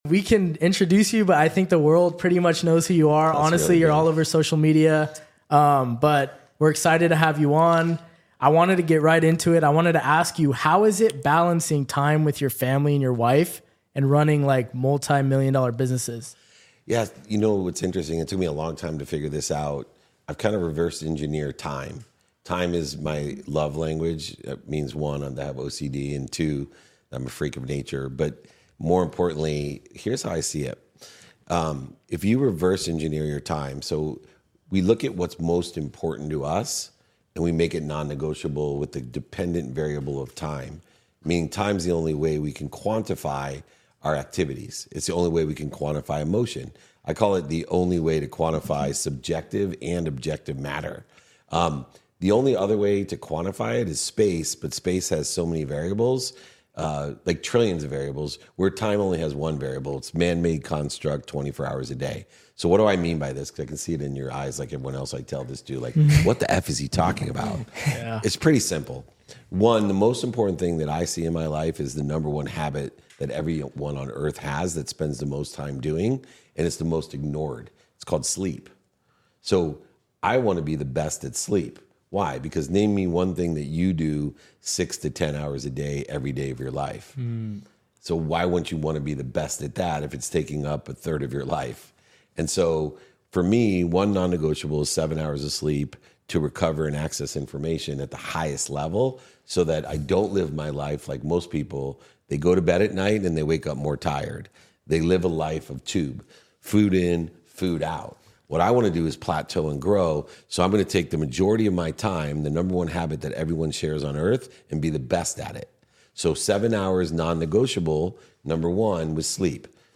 In this candid discussion, I emphasize the significance of never overachieving your self-image and the power of being kind to your future self. We discuss the crucial role of surrounding yourself with the right people and the value of building a community that supports and promotes each other.